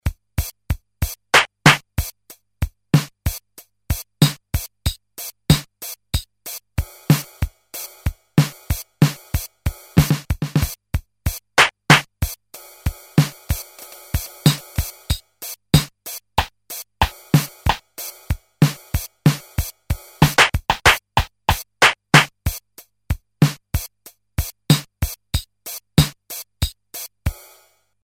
Programmable Digital Drum Machine (1981)
Classic American drum machine based on primitive 8-bits samples but great sound: this is the history of eighties music from New Order to Run DMC.
rhythm pattern 2